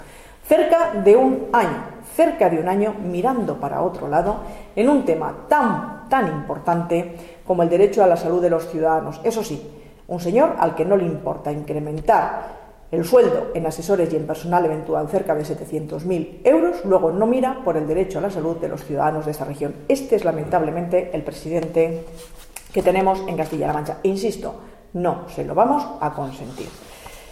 Ana_Guarinos_define_a_Page.mp3